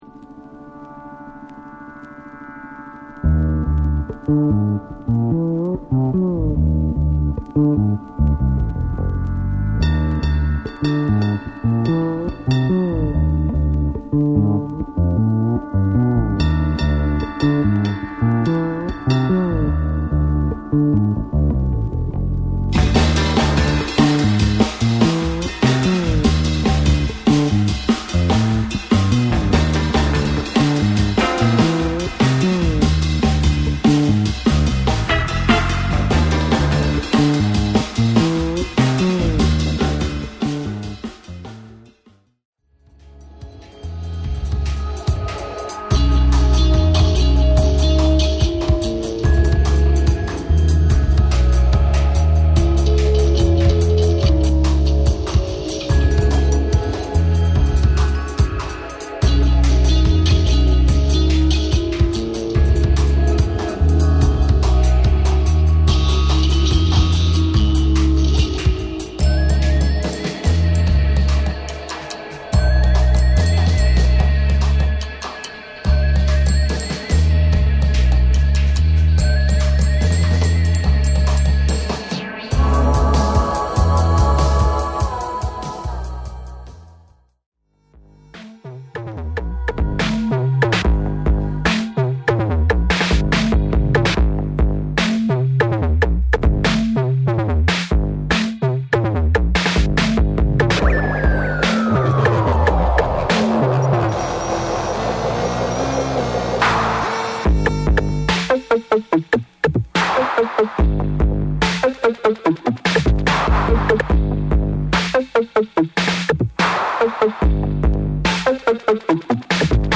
JAPANESE / MIX TAPE / NEW RELEASE(新譜)